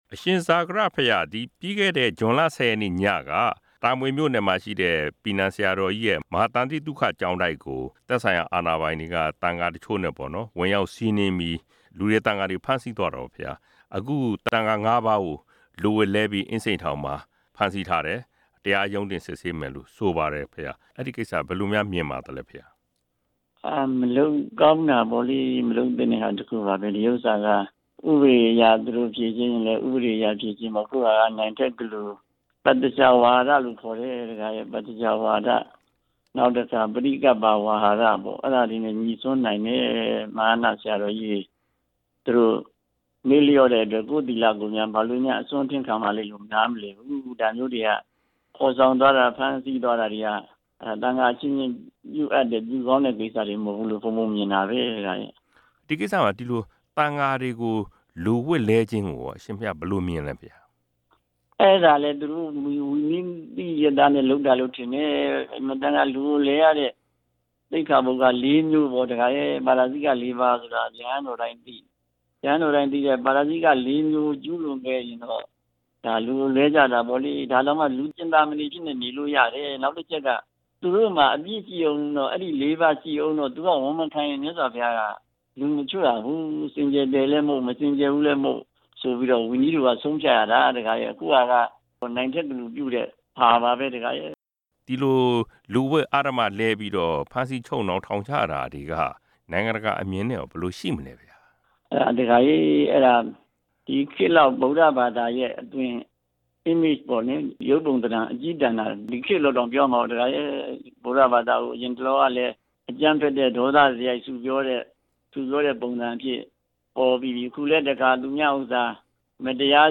သန္တိသုခကျောင်းက ရဟန်းငါးပါး ကို လူဝတ်လဲပြီး ထောင်ပို့တဲ့ ကိစ္စ မေးမြန်းချက်